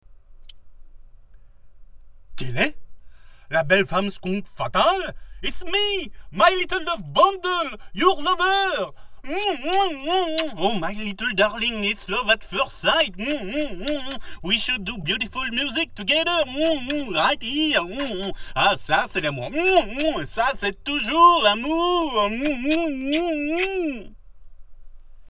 Man
French Canadian: For Narration: deep, rich, warm, resonant.
kanadisch-fr
Sprechprobe: eLearning (Muttersprache):